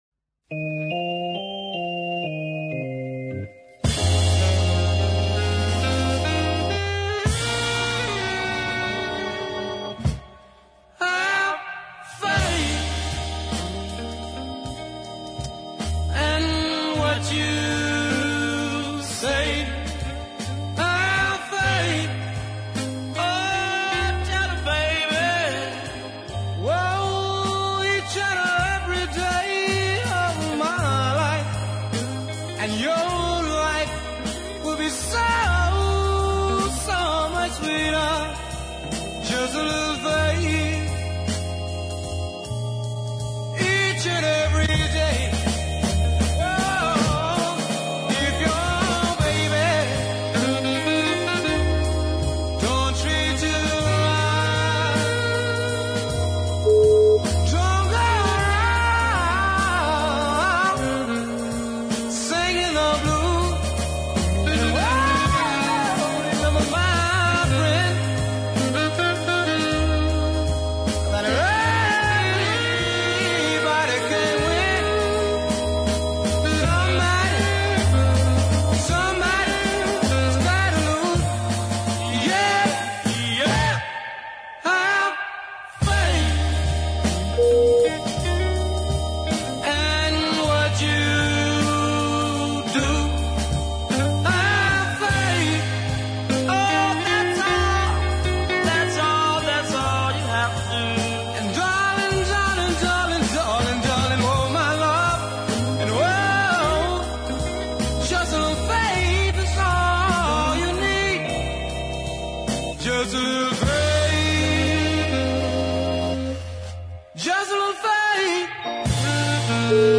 a gospel based number